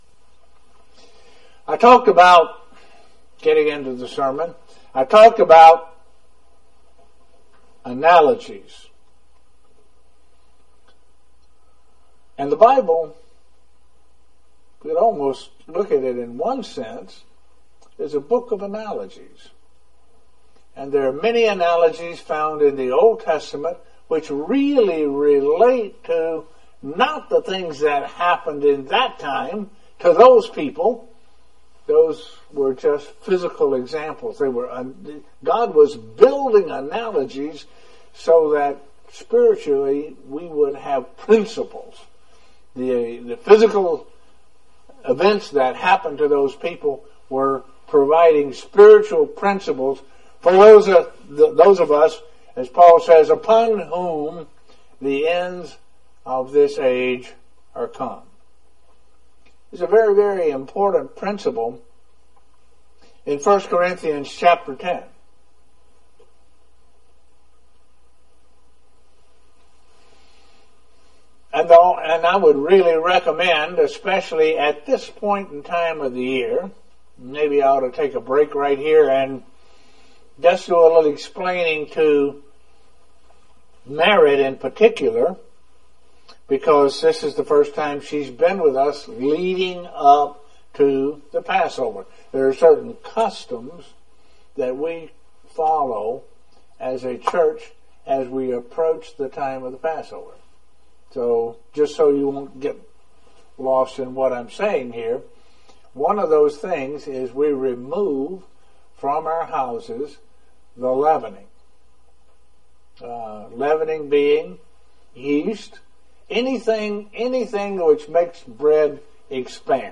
Given in Elmira, NY